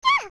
Eep!" sound when she is thrown by Link in Jabu-Jabu's Belly as a child is shared with Navi of when the Fairy hits the wooden wall in Kokiri Forest heading toward Link's house in the cutscene of being sent by the Great Deku Tree.